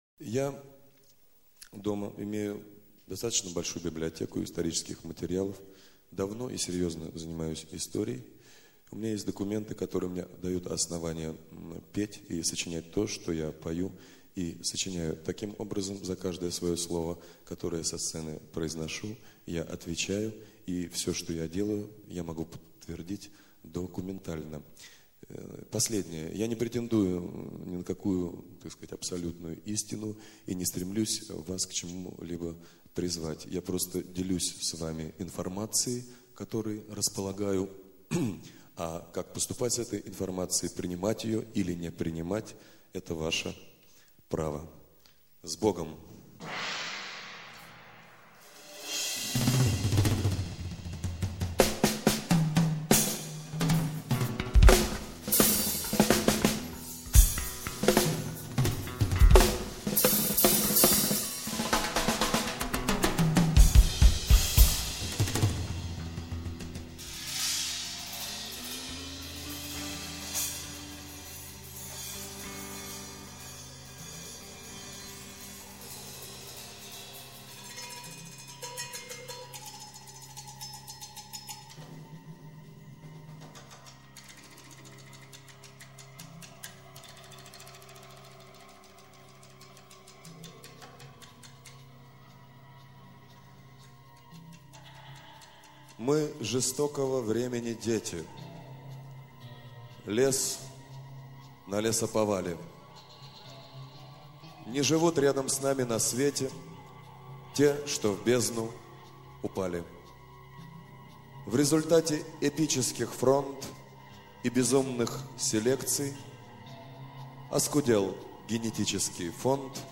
2. «Игорь Тальков – Декламация. – “Правда о России”.» /
Talkov-Deklamaciya.-Pravda-o-Rossii-stih-club-ru.mp3